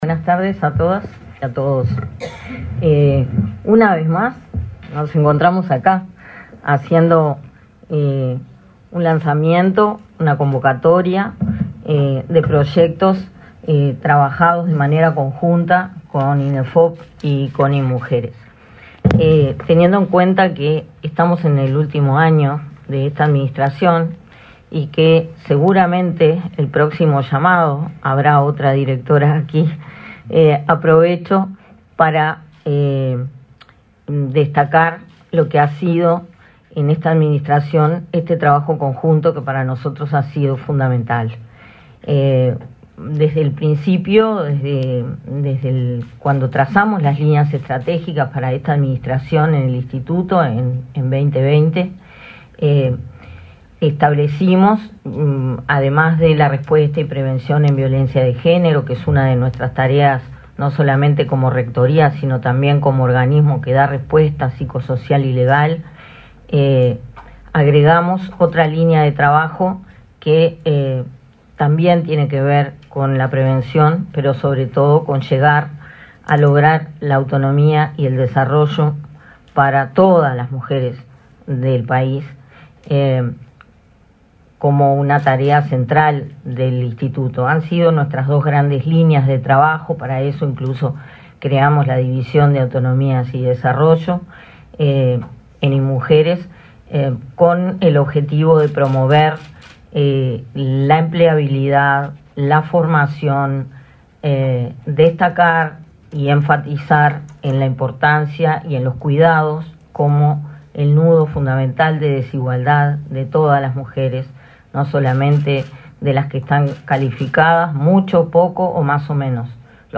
Acto por lanzamiento de proyectos de formación con perspectiva de género 06/06/2024 Compartir Facebook X Copiar enlace WhatsApp LinkedIn El Instituto Nacional de Empleo y Formación Profesional (Inefop) realizó, este 6 de junio, el acto de lanzamiento de la convocatoria 2024 para proyectos de formación profesional que potencien a mujeres para facilitar su inserción laboral. Participaron del evento el director general de Inefop, Pablo Darscht, y la directora de Inmujeres del Ministerio de Desarrollo Social, Mónica Bottero.